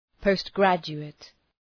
Προφορά
{pəʋst’grædʒu:ıt}